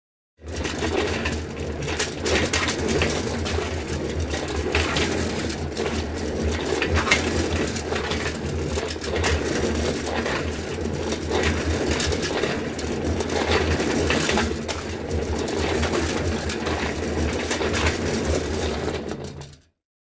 На этой странице собраны разнообразные звуки тележек и вагонеток: от легкого скрипа колес по асфальту до грохота тяжелых грузовых вагонеток.
Шум катящейся тележки